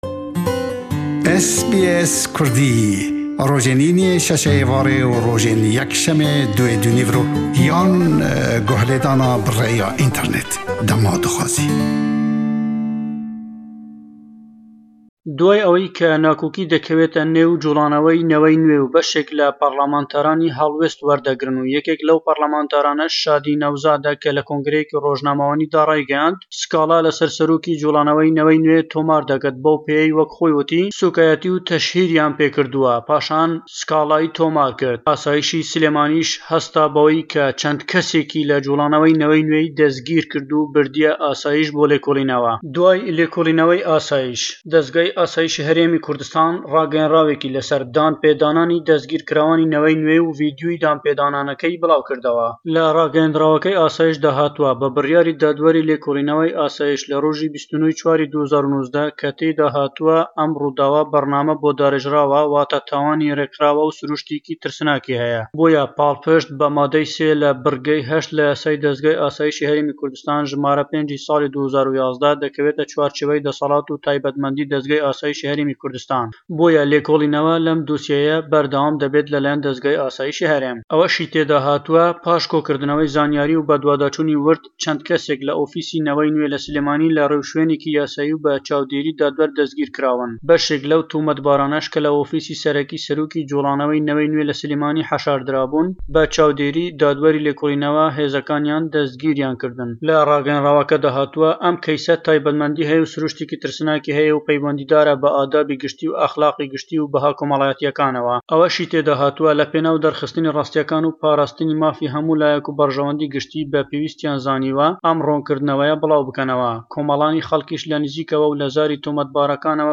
reports from Erbil on the latest regarding the arrest of Naway Nwe (new generation) party in Sulaimani.